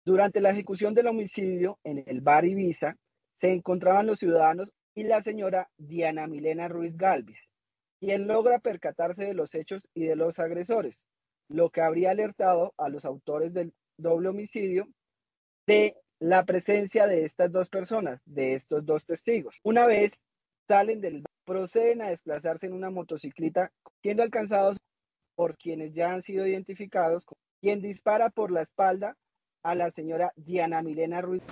Audiencia de imputación